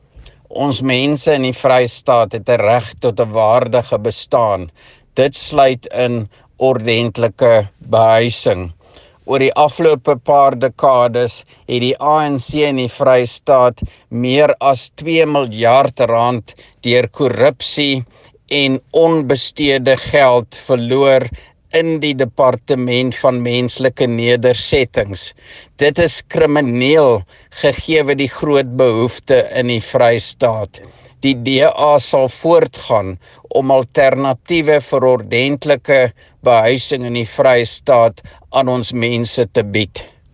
Afrikaans soundbites by Roy Jankielsohn MPL and Sesotho soundbite by Jafta Mokoena MPL